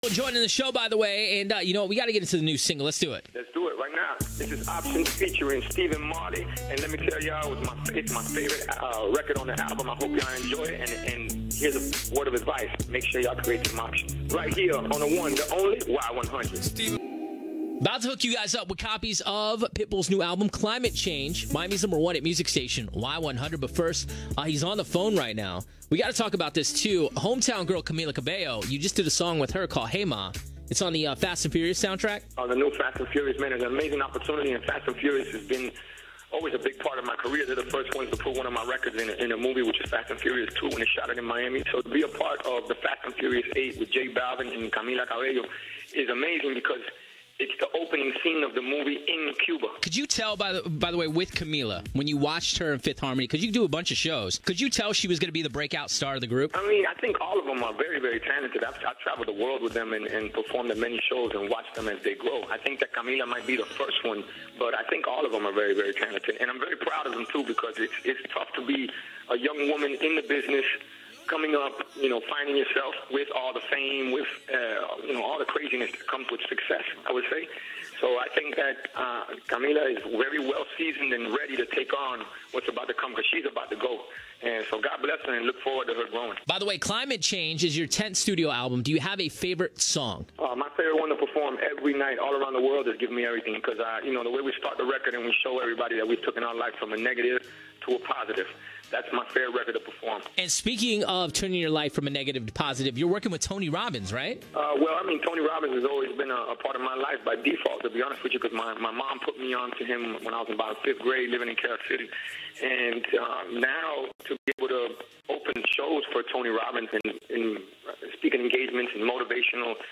Mr. 305 also called into Y100 Miami to discuss his new album, his favorite song to perform, what it was like to work with Camila Cabello, and more. Listen to the full interview below!